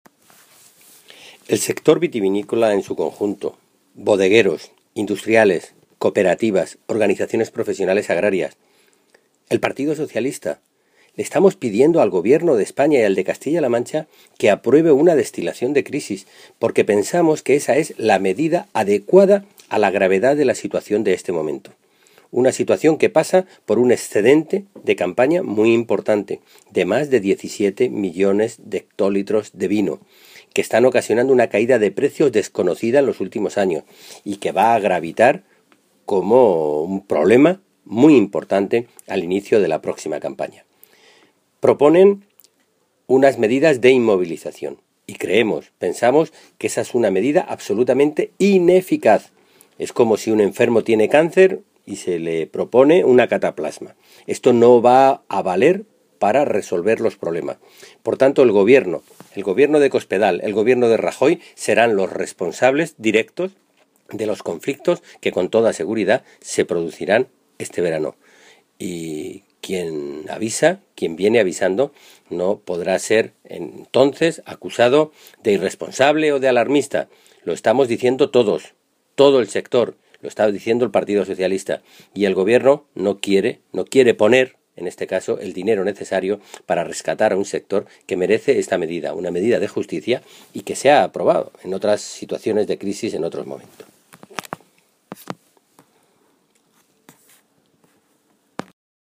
Alejandro Alonso, coordinador del grupo de parlamentarios nacionales del PSOE de Castilla-La Mancha
Cortes de audio de la rueda de prensa